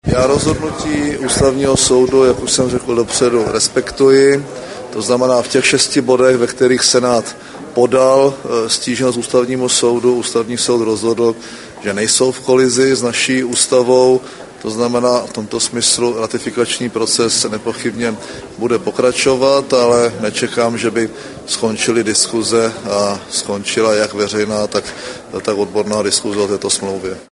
Prime Minister´s Press Statement on the ruling of the Constitutional Court of the Czech Republic on the Lisbon Treaty.
Audiozáznam vyjádření premiéra Mirka Topolánka